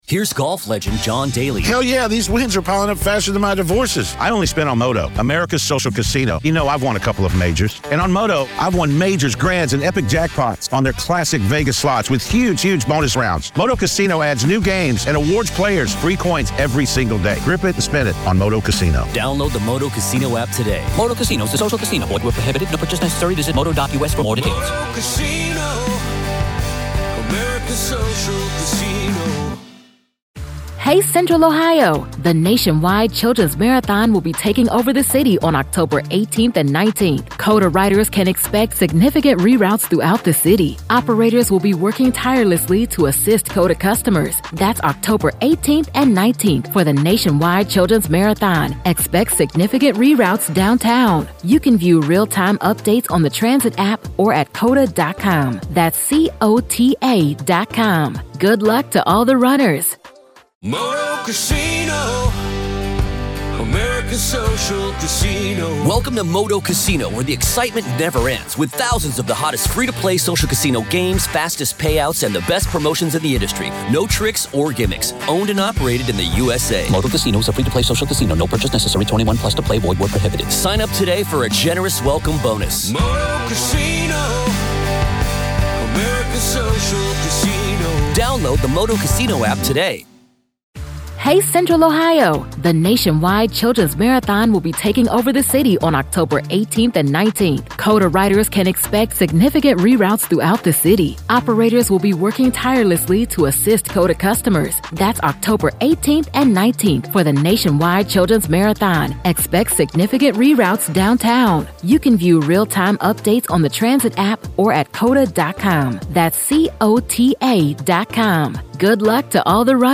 Dec 01, 2023, 06:00 PM Headliner Embed Embed code See more options Share Facebook X Subscribe The Delphi murder case, ensnaring Richard Allen, has spiraled into a vortex of alarming allegations and judicial controversies, raising critical questions about the integrity of the American justice system. In a candid discussion with psychotherapist and author